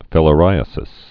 (fĭlə-rīə-sĭs)